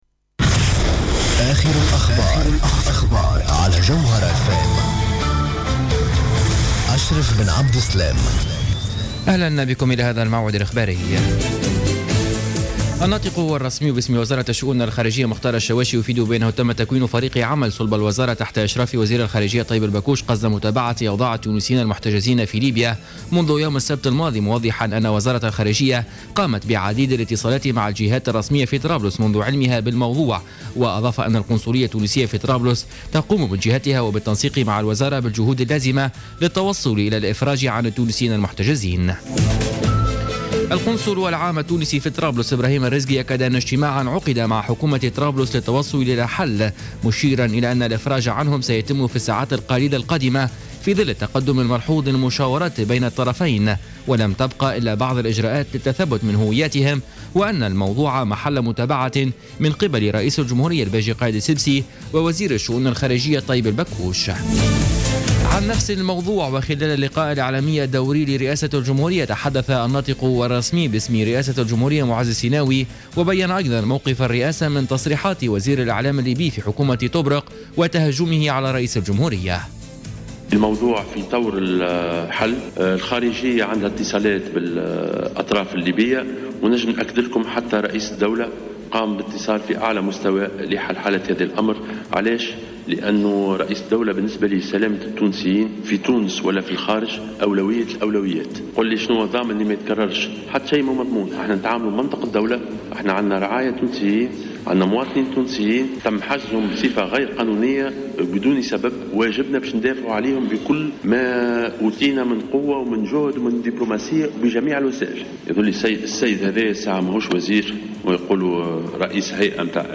نشرة أخبار منتصف الليل ليوم الثلاثاء 19 ماي 2015